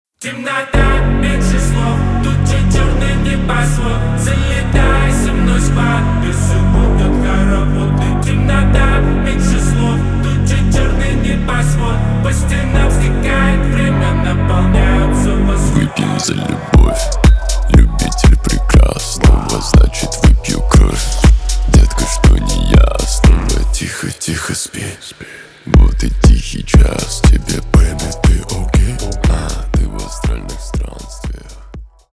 на русском клубные